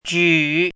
怎么读
举 [jǔ]
ju3.mp3